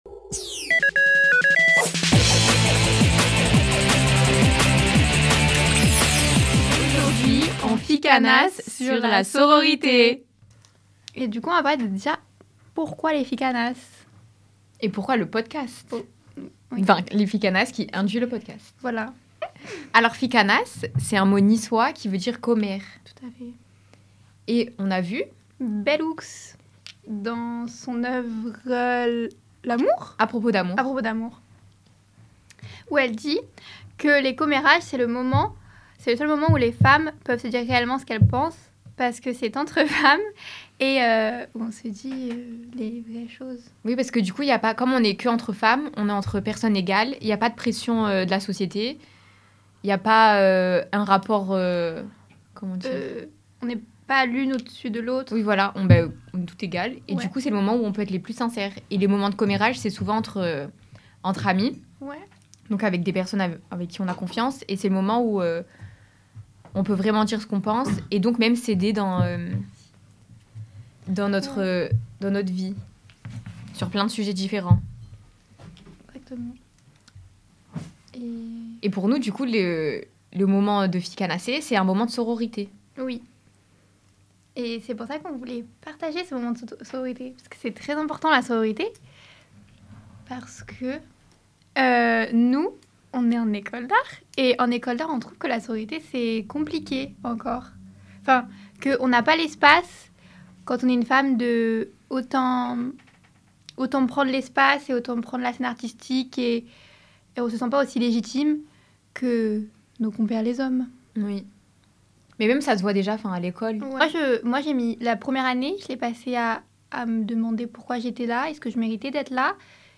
Les ficanasses c’est un podcast de discussions en sororité.